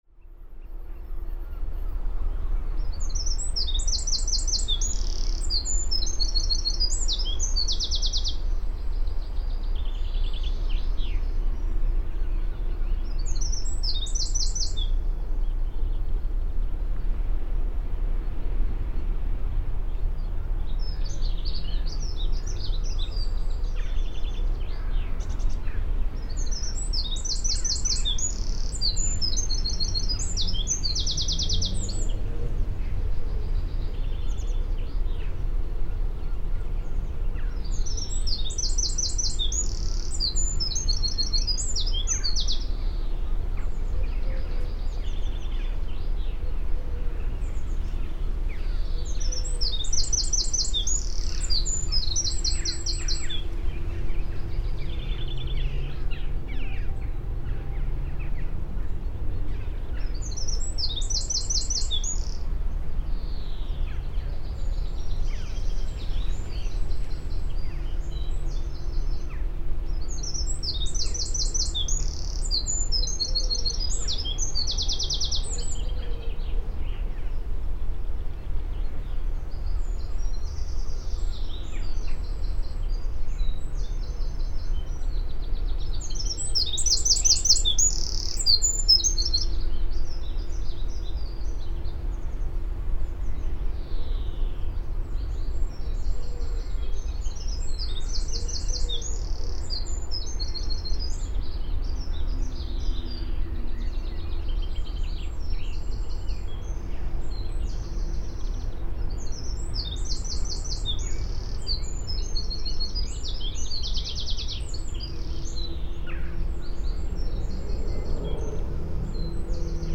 This is a recording I made early in the morning before I took the ferry back to the continent.
It was quite noisy due to the busy harbour of Folkstone, but I like the depth it creates.
PFR11922, 1-10, 160702 Northern Wren Troglodytes troglodytes, song, Dunnock Prunella modularis, song
Folkstone, UK, MS-Stereo, MKH30 + MKH8020